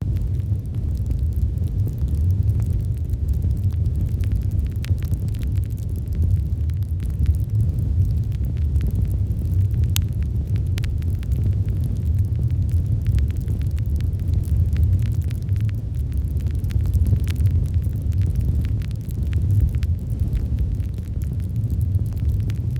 fire.ogg